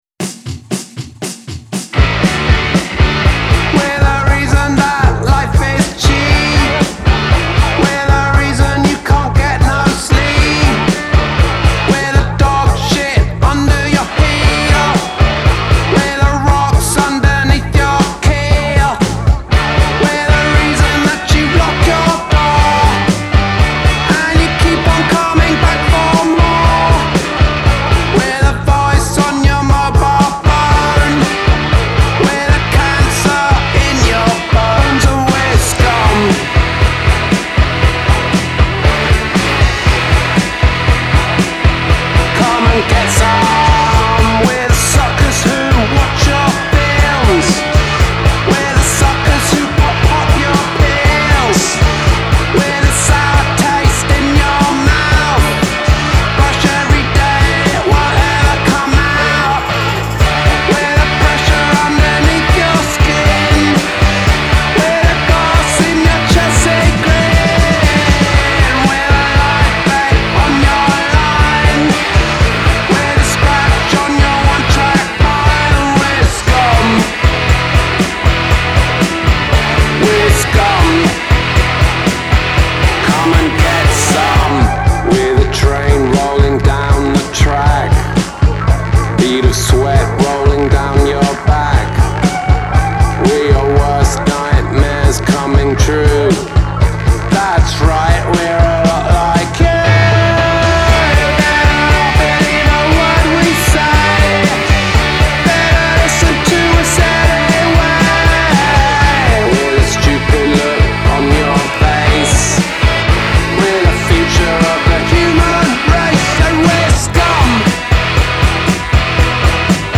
off-kilter post-punk bangers
abrasive minimalist post-punk feel
Velvets inspired grunge guitar